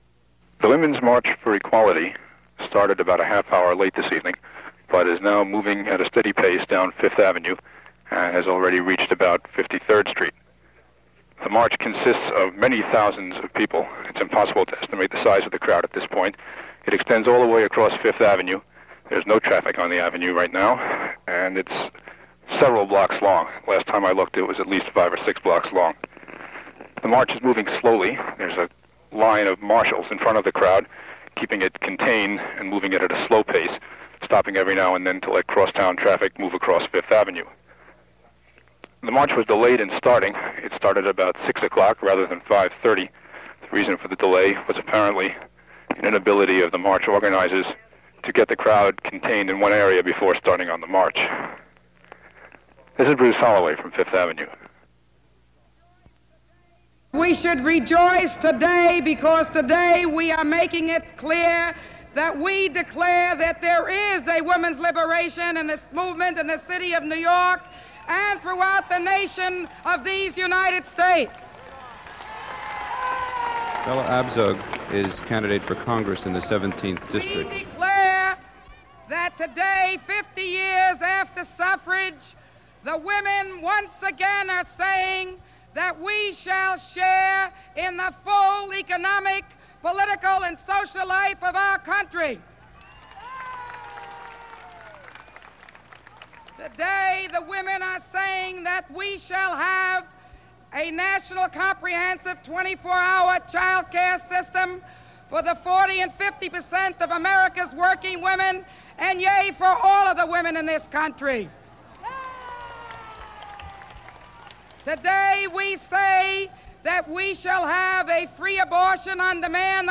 As a reminder of the protests, the struggle and the message on this International Women’s Day, Here is an excerpt of the Women’s March in New York City, as relayed by WBAI and its day-long live broadcast on August 26, 1970.